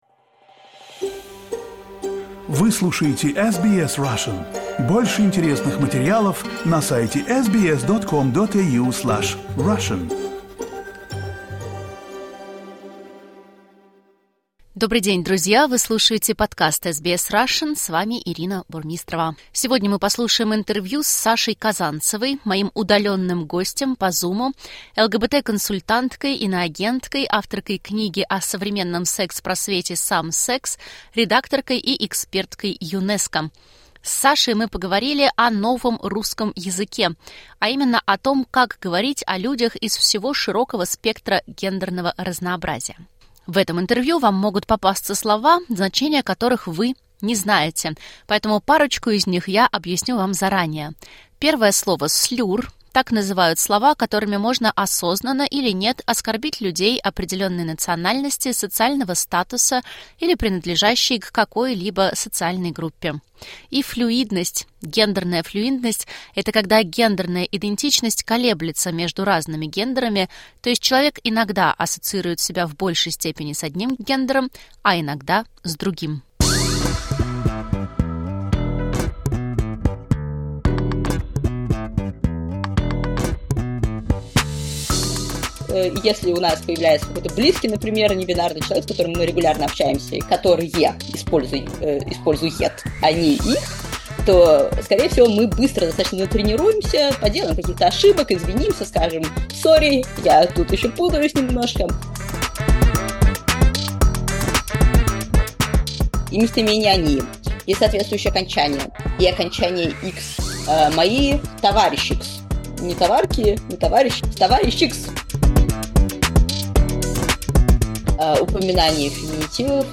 An interview about the “new” Russian language. How to talk about people from across a wide range of gender diversity?